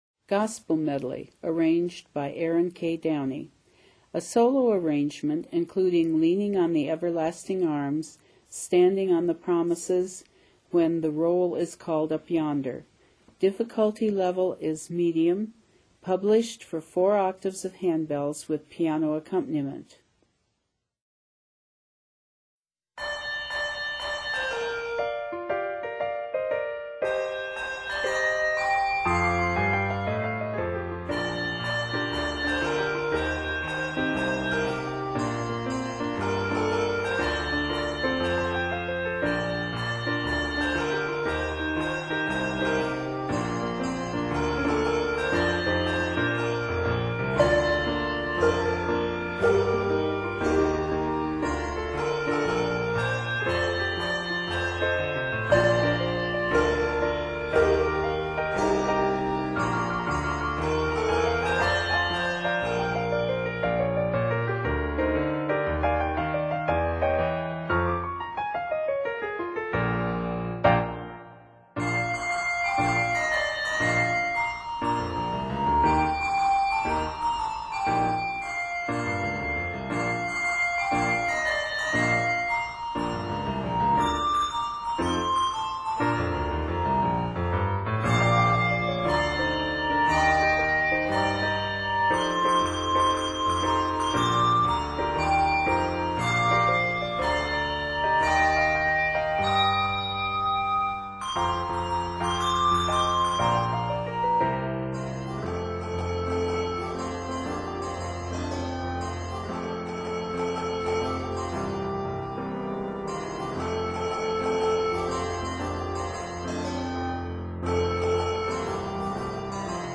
Instrument: Piano